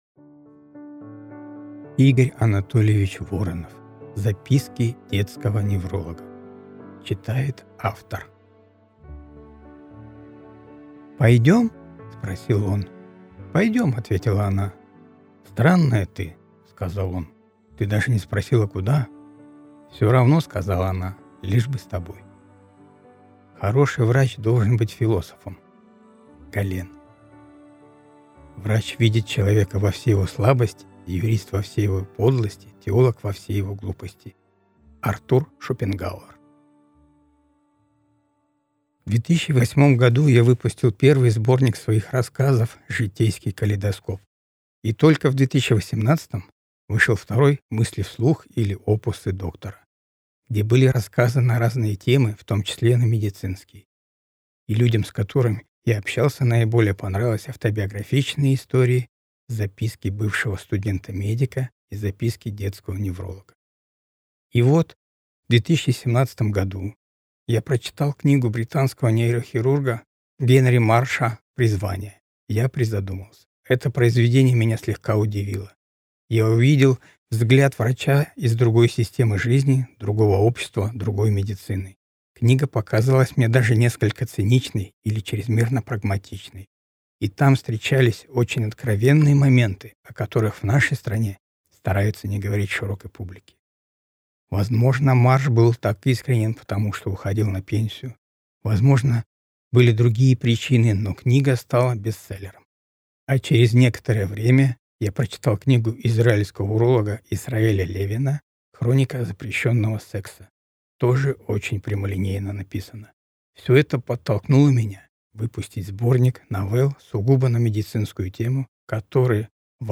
Аудиокнига Записки детского невролога | Библиотека аудиокниг